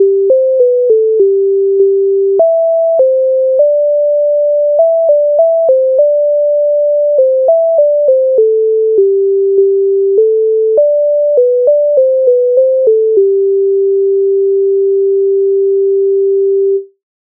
MIDI файл завантажено в тональності G-dur
Нарвала квіточок Українська народна пісня з обробок Леонтовича с,215
Ukrainska_narodna_pisnia_Narvala_kvitochok.mp3